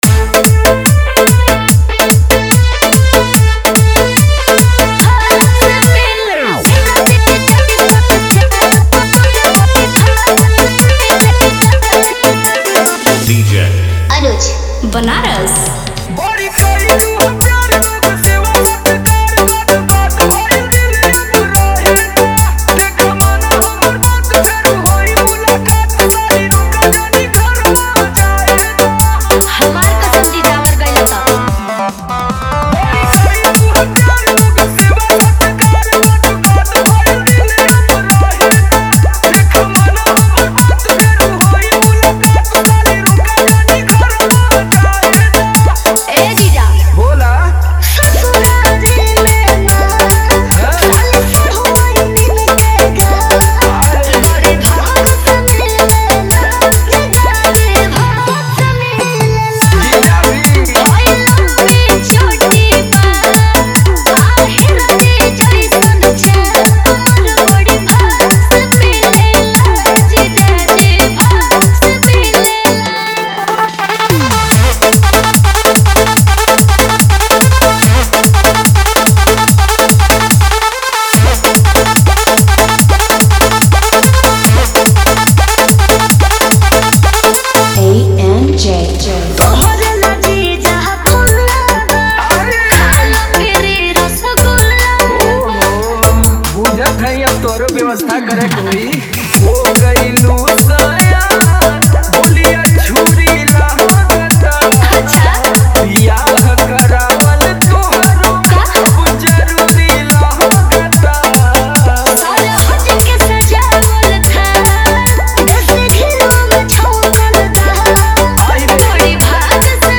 न्यू भोजपुरी डीजे गाना 2025